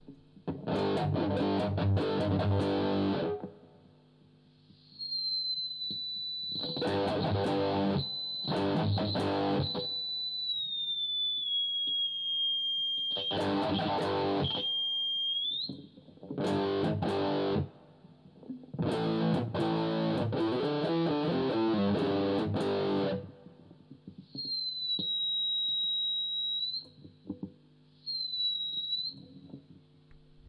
Patchbox: Kreischen im Effektloop
Die Patchboxen sind soweit auch fertig, nur leider habe ich mit Störgeräuschen zu kämpfen und kann diese nicht wirklich zuordnen.